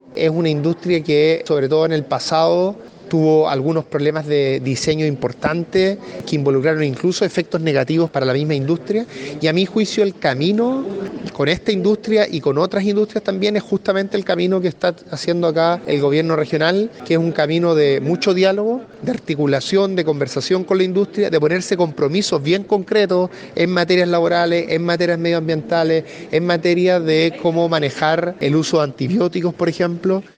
La autoridad participó del encuentro de la sustentabilidad de la región de Los Lagos, instancia donde La Radio aprovechó de consultarle dos temas que son de relevancia local, como es la Ley de Pesca y la industria salmonera.